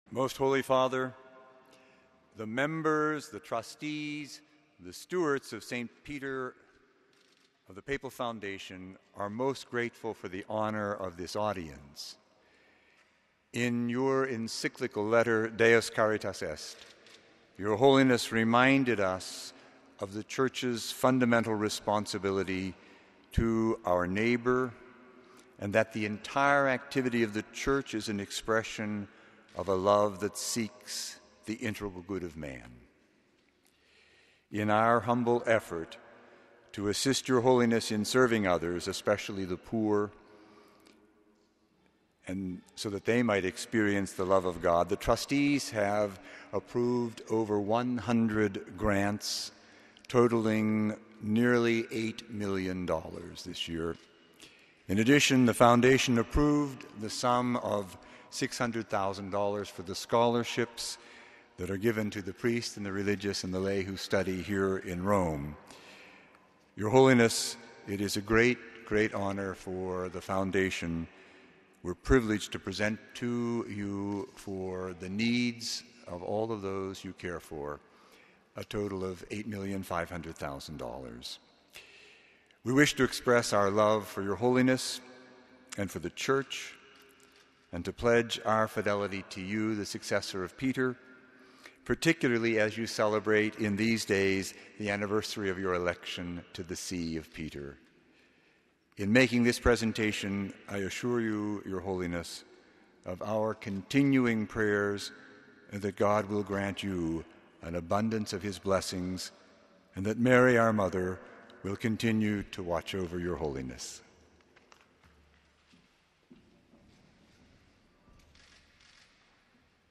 Below the text and audio of Pope Benedict XVI’s address to the US based Papal Foundation